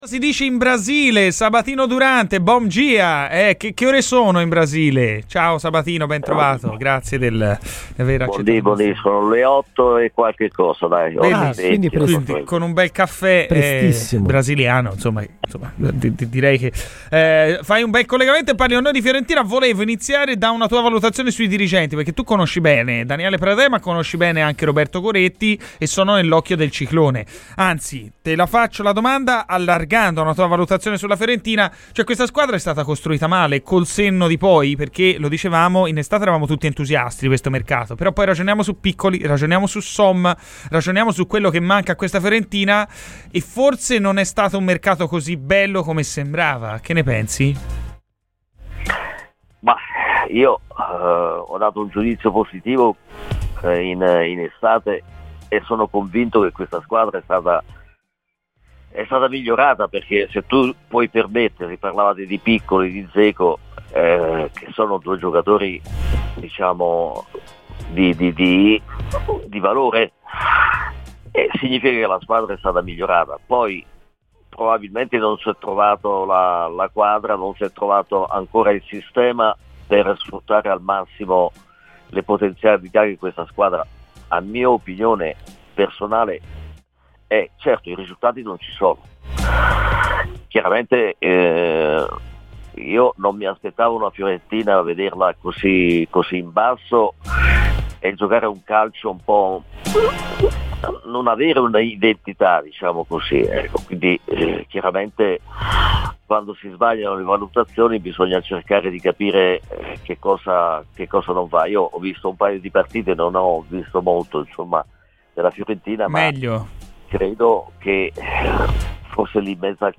Ascolta l'intervista completa su Radio FirenzeViola.